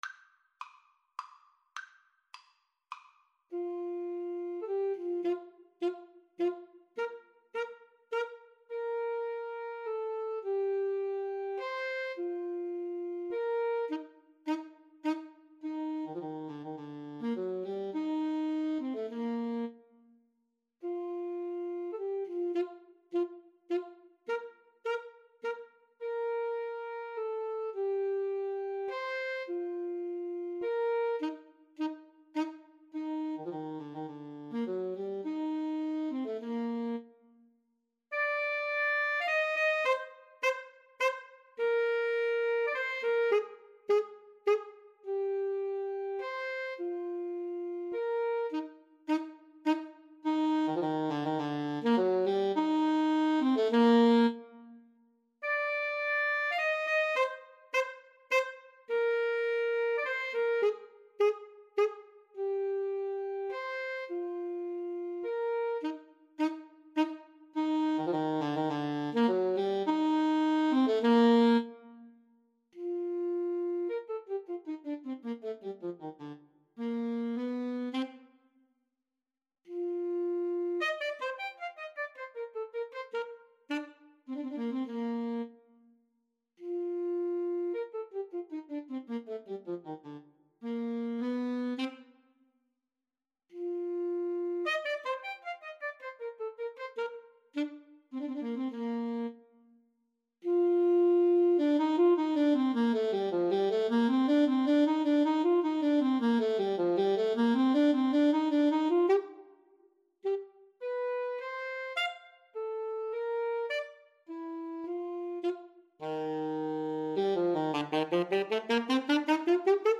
3/4 (View more 3/4 Music)
Con Grazia = c. 104